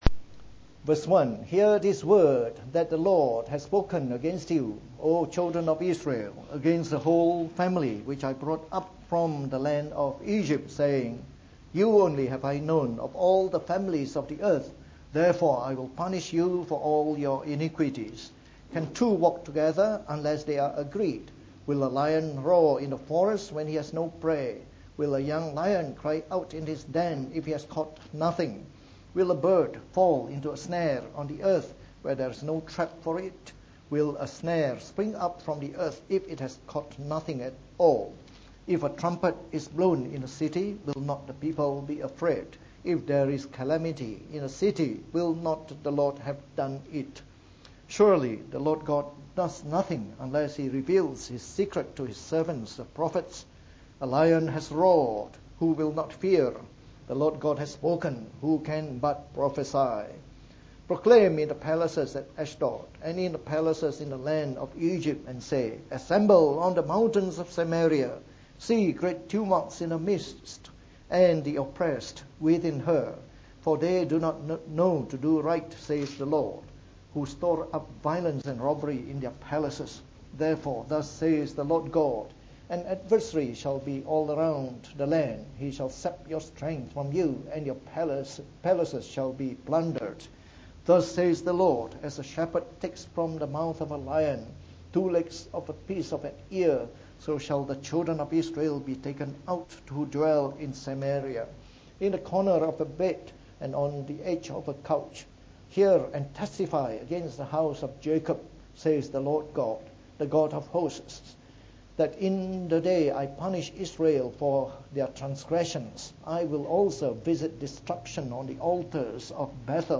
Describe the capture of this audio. From our series on the Book of Amos delivered in the Morning Service.